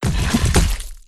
Death2.wav